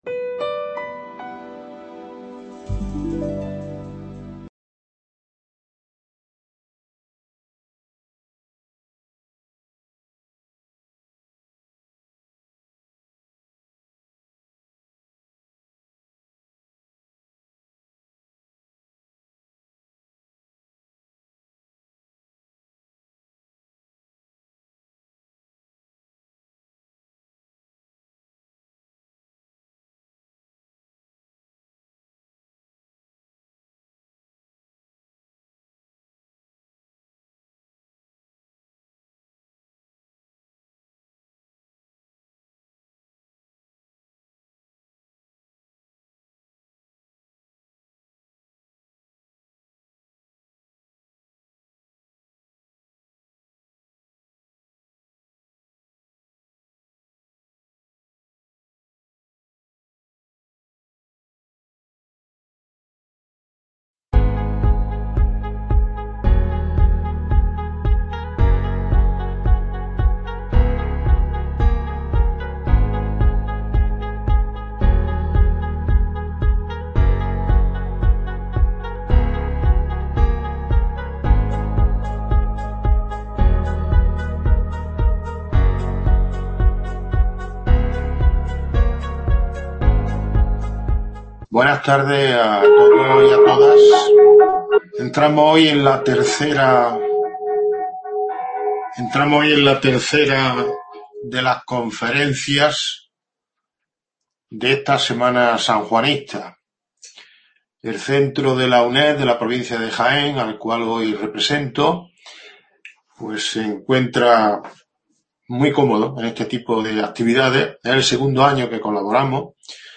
imparte esta conferencia en el auditorio del Hospital de Santiago de Úbeda, dentro de la "43+1 Semana Sanjuanista". Desde 1977 la comunidad de Carmelitas Descalzos organiza cada año en Úbeda un ciclo de conferencias y actos litúrgicos y artísticos dedicados a San Juan de la Cruz.